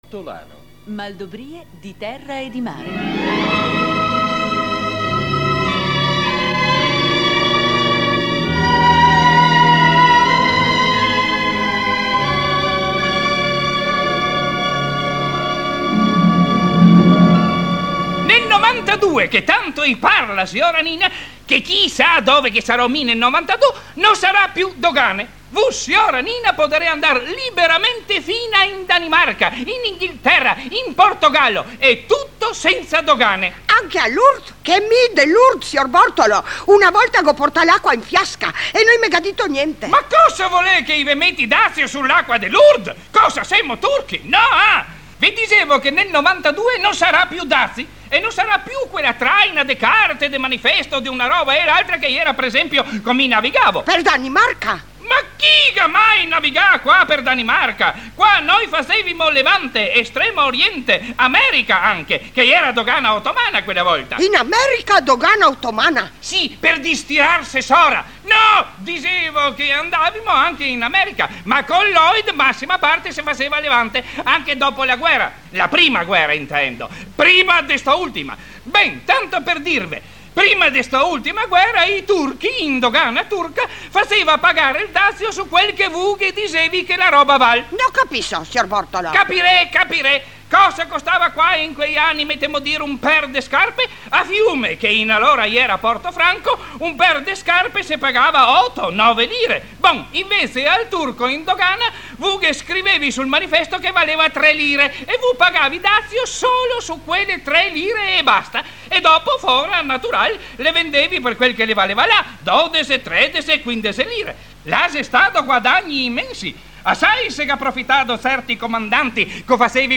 Ho ritenuto di fare cosa utile mettendo a disposizione queste testimonianze sonore, anche se modeste sotto l'aspetto tecnico, perché le registrazioni delle Maldobrìe sembrano introvabili.